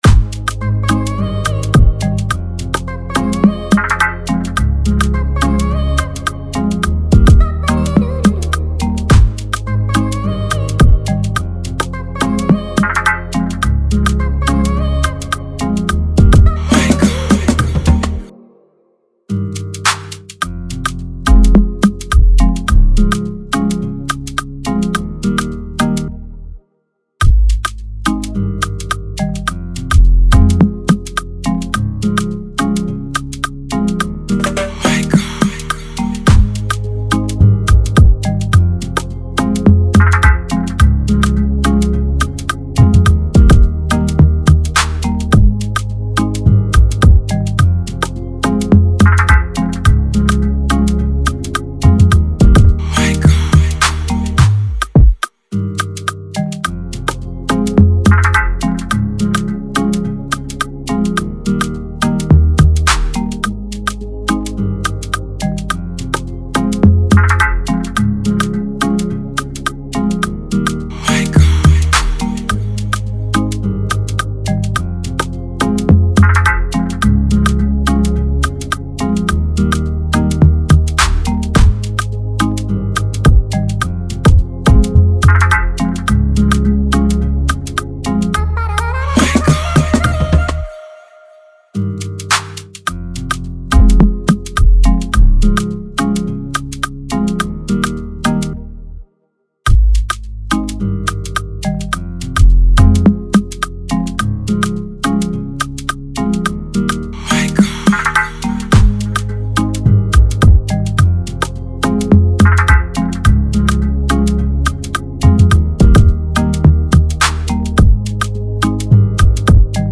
GENRE: Afro